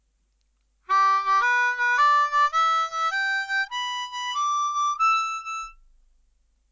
It’s the classic walking bass sequence.
We’re using a C major diatonic.
Over the I chord